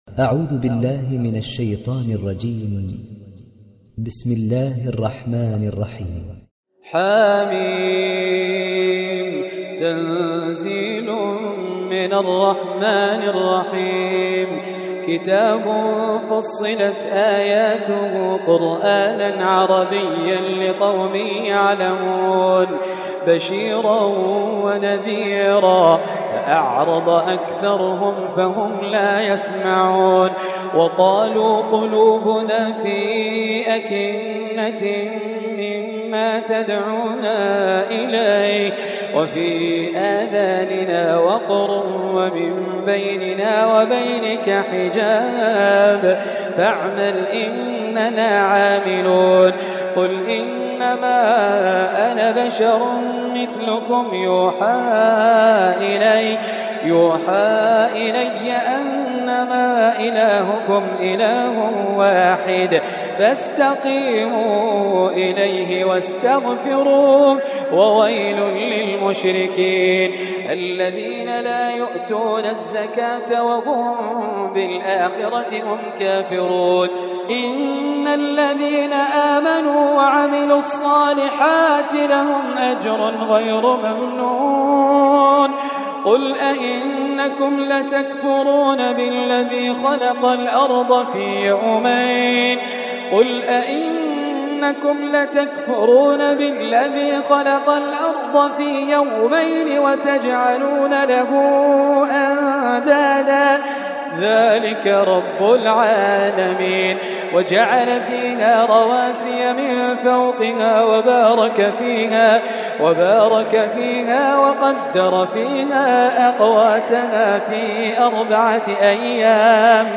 دانلود سوره فصلت mp3 خالد الجليل روایت حفص از عاصم, قرآن را دانلود کنید و گوش کن mp3 ، لینک مستقیم کامل